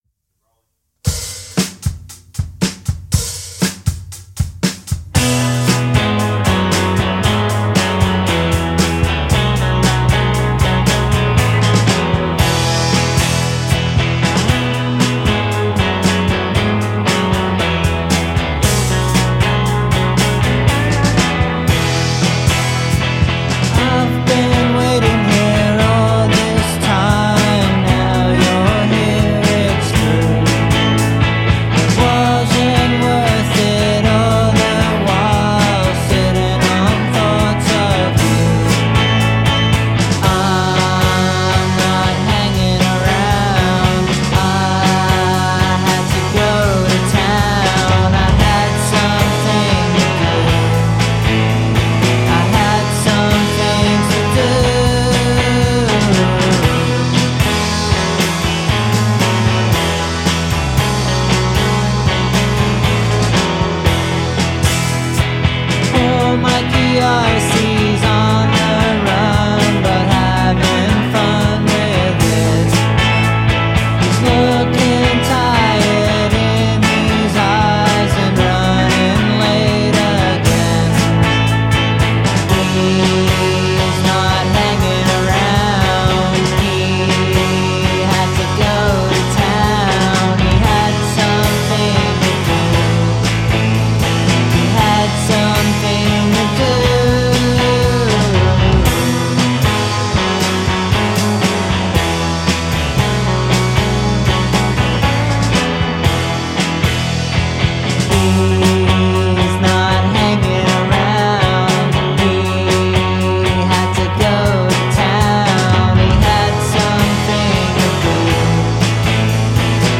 c'è una pigrizia ricercata e dolce nel suono